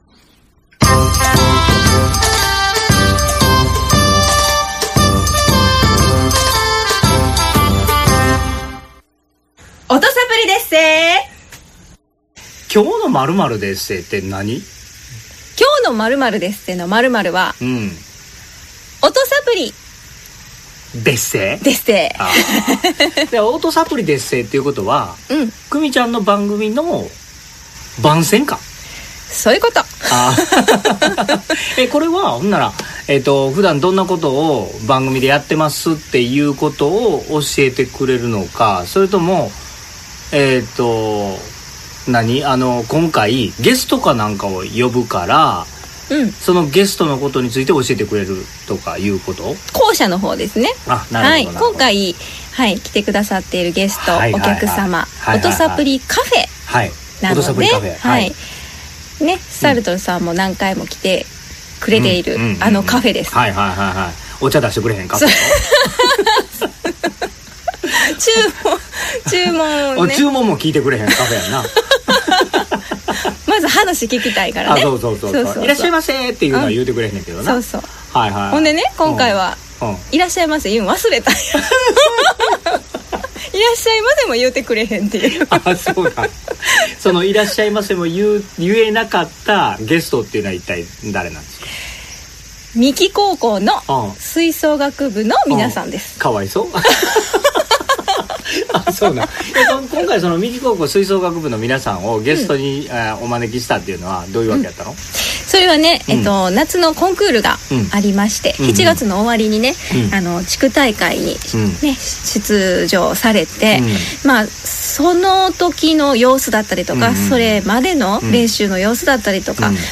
ぶどうの販売、梱包作業、又芽取りが終わってからのぶどう園での収録でした。 虫の声がバックミュージックとなっています。虫の奏でるハーモニーもお楽しみください♪